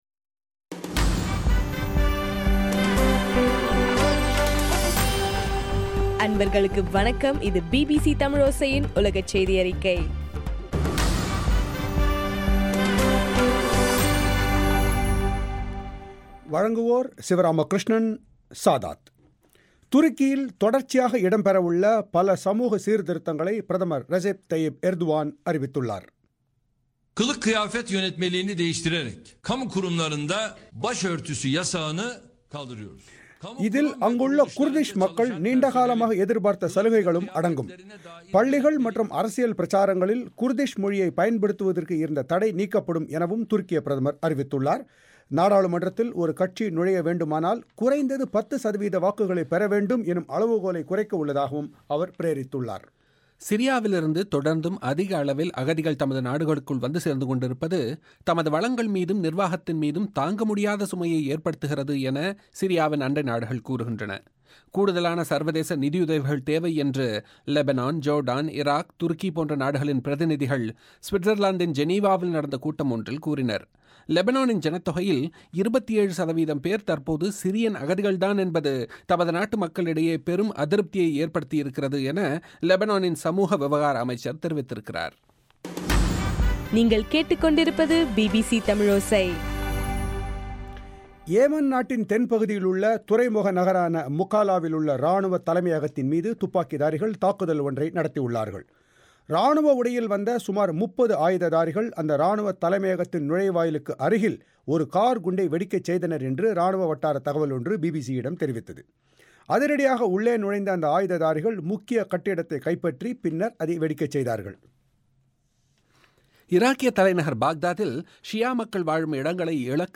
செப்டம்பர் 30 இல் ஒலிபரப்பான பிபிசி தமிழோசையின் 5 நிமிட உலகச் செய்திகள்.